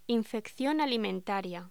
Locución: Infección alimentaria
voz